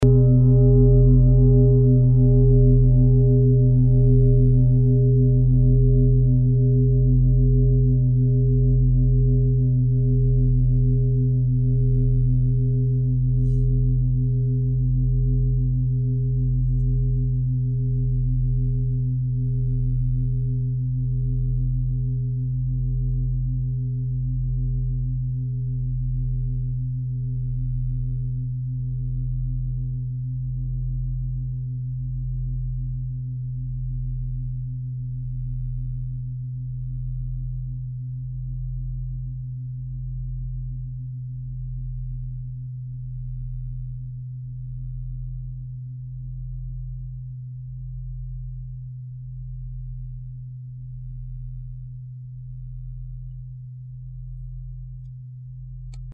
Die große Klangschale wurde in Handarbeit von mehreren Schmieden im Himalaya hergestellt.
Hörprobe der Klangschale
Was den Klang anbelangt, sind handgearbeitete Klangschalen immer Einzelstücke.
(Ermittelt mit dem Gummischlegel)
Die Wasserfrequenz liegt bei 178,81 Hz und dessen tieferen und höheren Oktaven. In unserer Tonleiter ist das in der Nähe vom "Fis".
fuss-klangschale-1.mp3